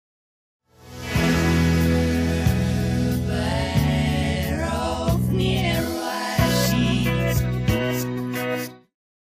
Genere: christian rock
Rovesciato
Incomprensibile